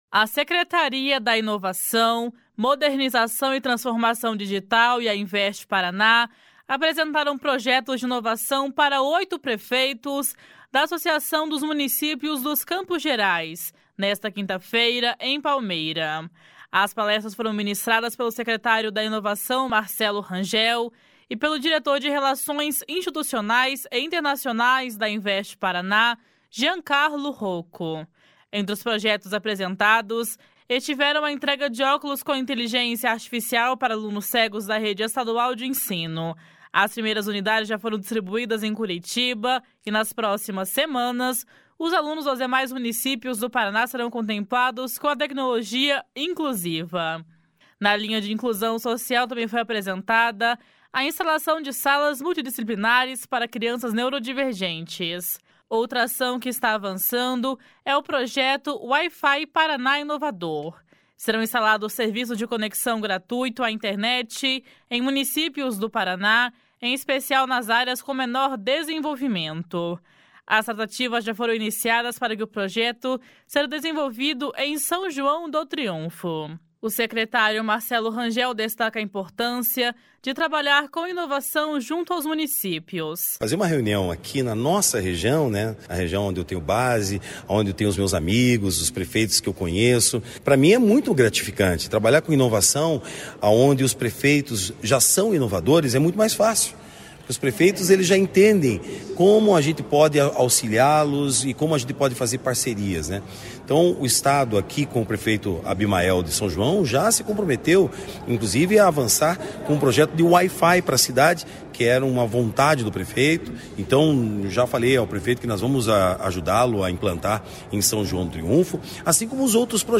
// SONORA MARCELO RANGEL //